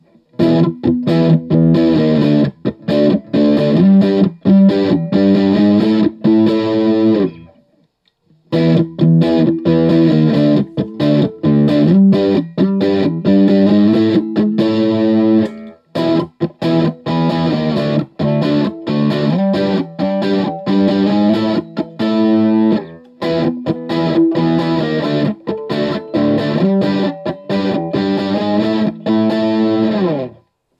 All recordings in this section were recorded with an Olympus LS-10.
All guitar knobs are on 10 for all recordings.
Basic 800
Chords
For each recording, I cycle through all of the possible pickup combinations, those being (in order): neck pickup, both pickups (in phase), both pickups (out of phase), bridge pickup.